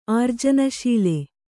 ♪ ārjanaśile